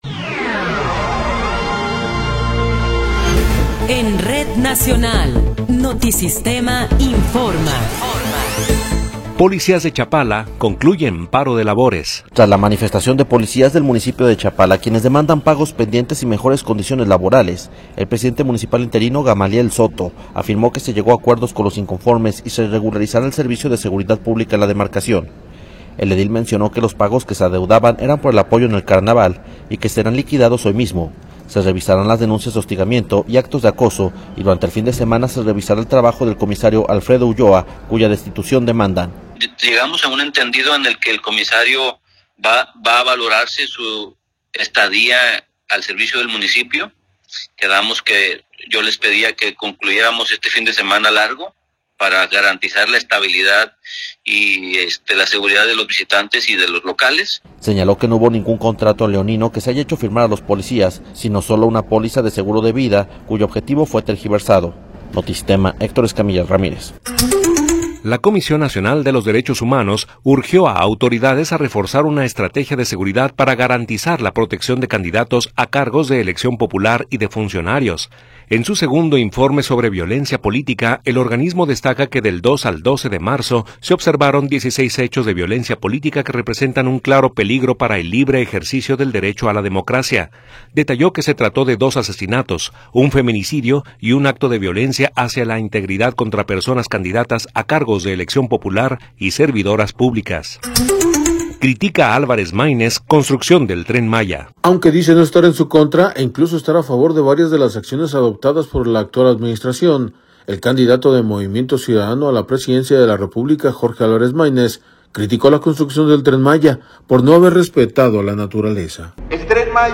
Noticiero 17 hrs. – 15 de Marzo de 2024
Resumen informativo Notisistema, la mejor y más completa información cada hora en la hora.